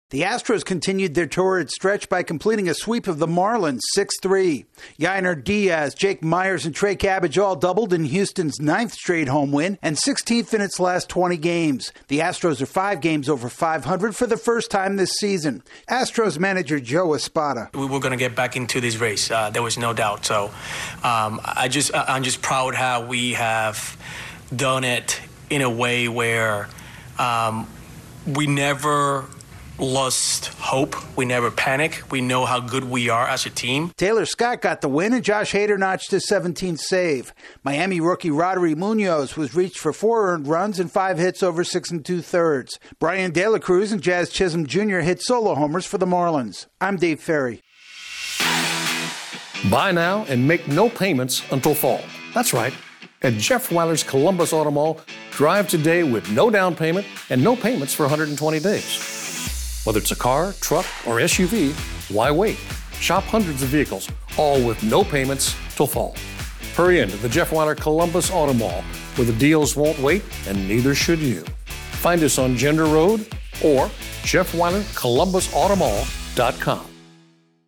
The Astros polish off a perfect series by doubling up the Marlins. AP correspondent